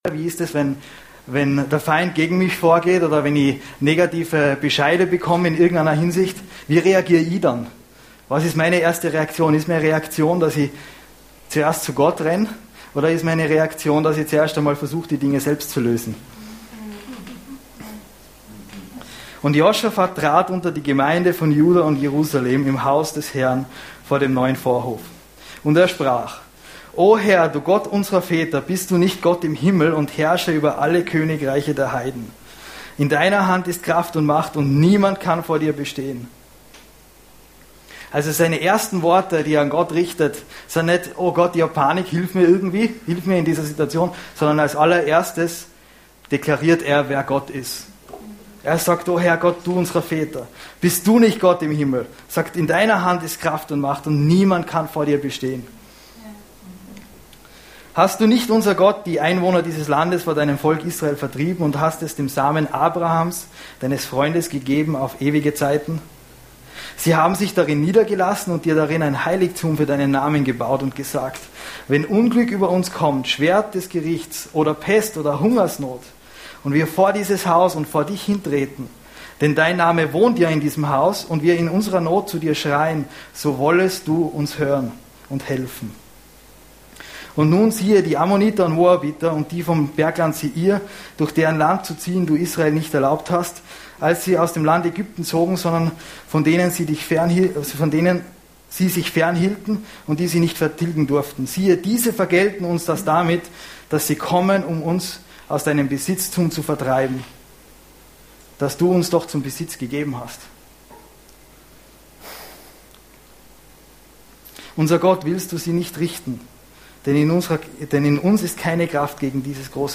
Sermons Archiv - Seite 6 von 12 - Freikirche Every Nation Innsbruck